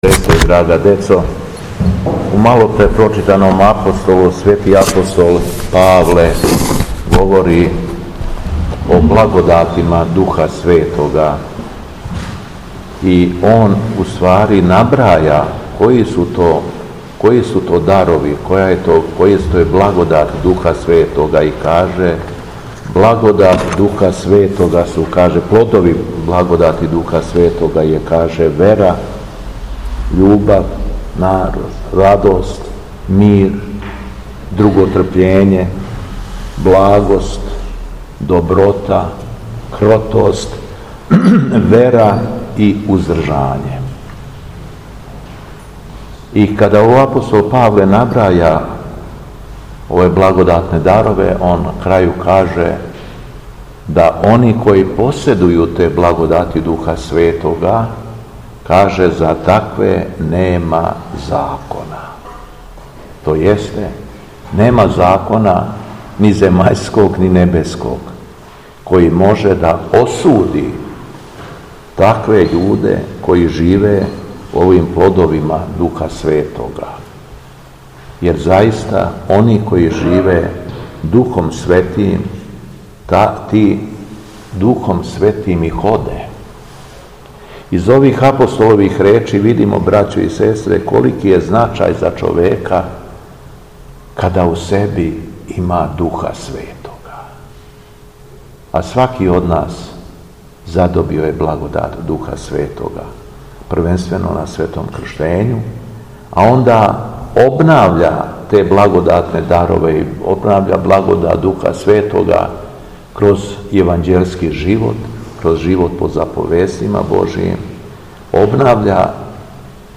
СВЕТА АРХИЈЕРЕЈСКА ЛИТУРГИЈА У ХРАМУ СВЕТОГ ЦАРА ЛАЗАРА У КРАГУЈЕВАЧКОМ НАСЕЉУ БЕЛОШЕВАЦ - Епархија Шумадијска
Беседа Његовог Високопреосвештенства Митрополита шумадијског г. Јована